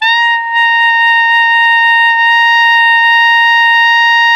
ALTO  PP B 4.wav